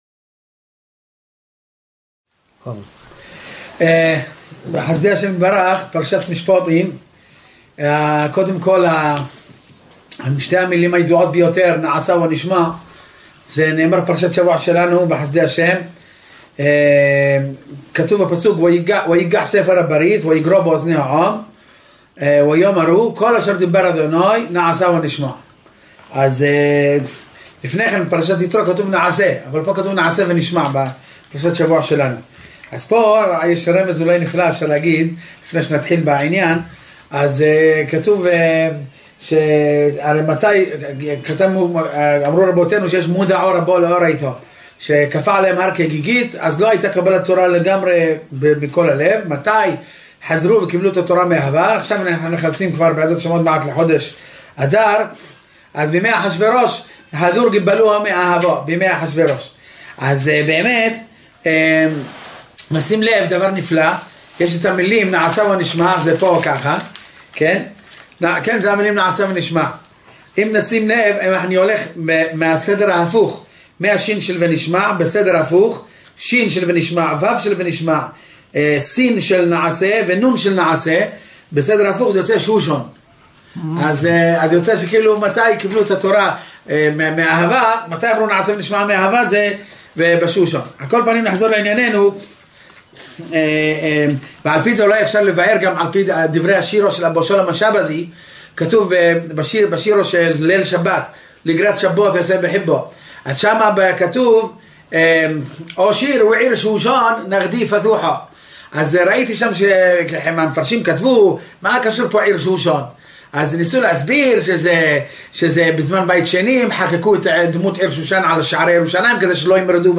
מדבר בכאב על מצב בחורי העדה שגדלים ללא מורשת אבות וללא חוסן רוחני מסכנות הרחוב הציוני